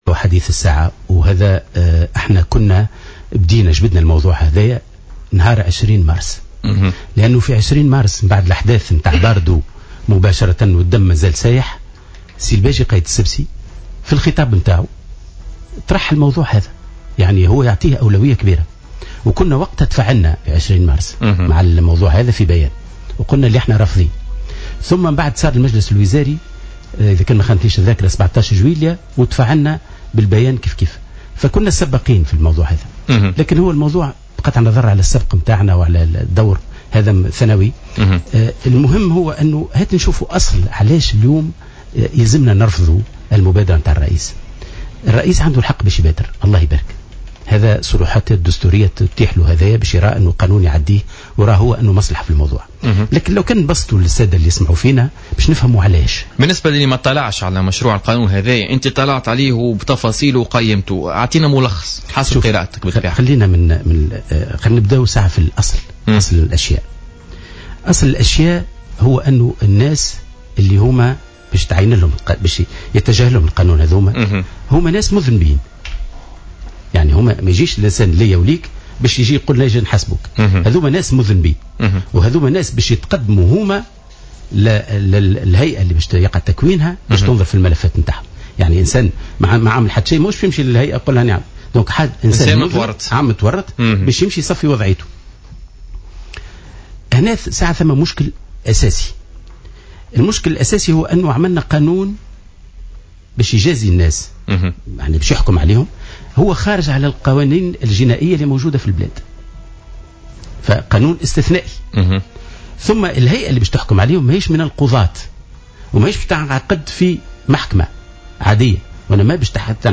جدد أمين عام حزب الإتحاد الشعبي الجمهوري لطفي المرايحي ضيف "بوليتيكا" اليوم الخميس 3 ىسبتمبر 2015 رفض حزبه مبادرة رئيس الجمهورية المتعلقة بقانون المصالحة الإقتصادية.